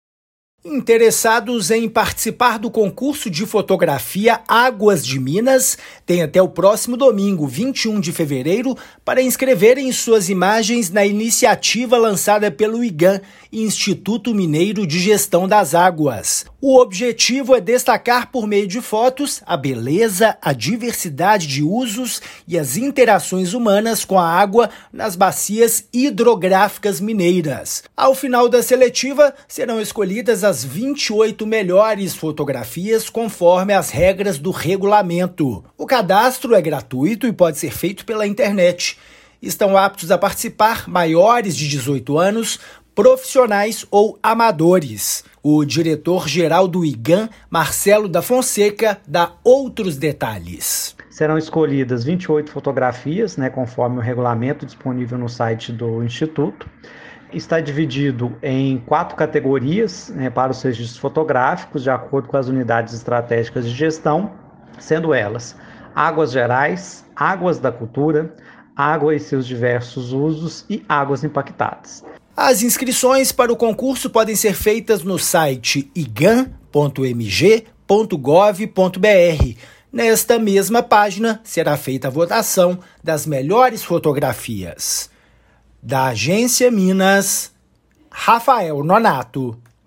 Concurso de fotografia Águas de Minas tem intuito de destacar, por meio de imagens, a beleza, a diversidade de usos e as interações humanas com a água e as bacias hidrográficas. Ouça a matéria de rádio.
MATÉRIA_RÁDIO_CONCURSO_ÁGUAS_DE_MINAS.mp3